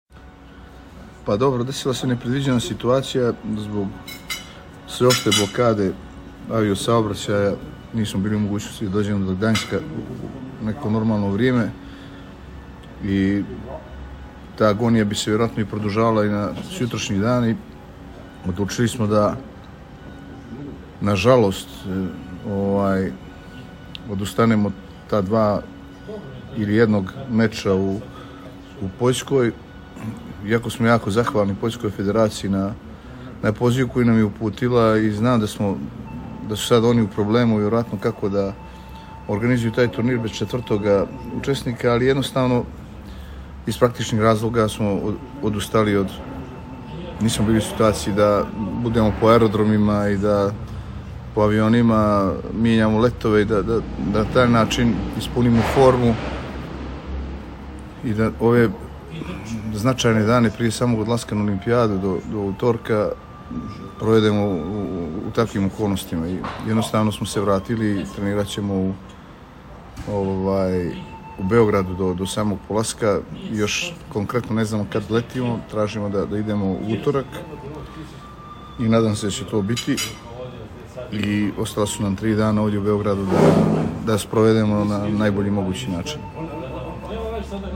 Izjava Igora Kolakovića